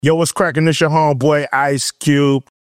ICE CUBE – AD – A
ICE-CUBE-AD-A.mp3